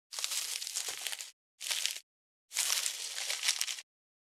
650コンビニ袋,ゴミ袋,スーパーの袋,袋,買い出しの音,ゴミ出しの音,袋を運ぶ音,
効果音